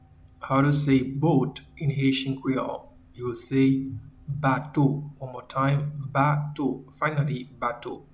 Pronunciation:
Boat-in-Haitian-Creole-Bato.mp3